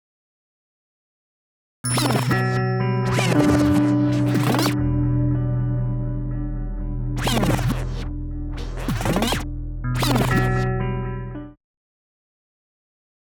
Creating music with code
“Guitar ‘n’ Scratch”, an eerie guitar and vinyl scratch composition.
guitar-n-scratch.wav